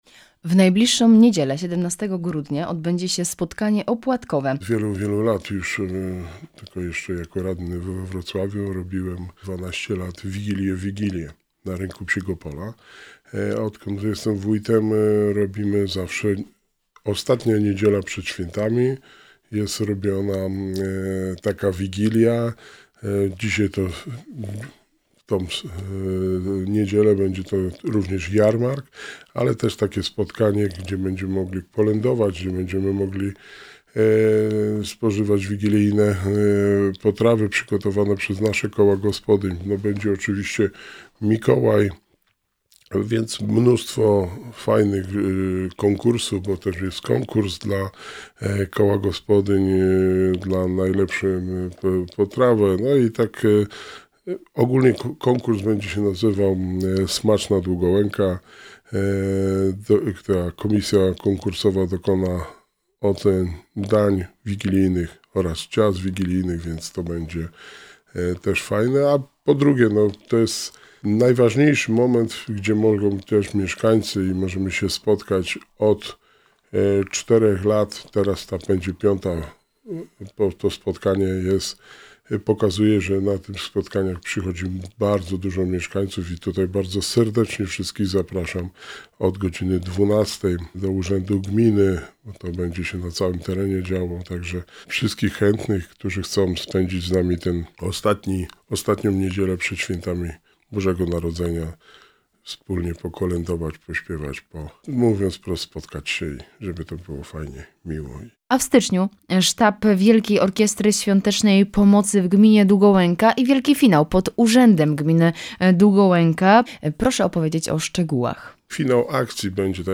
W studiu Radia Rodzina gościł Wojciech Błoński, wójt Gminy Długołęka.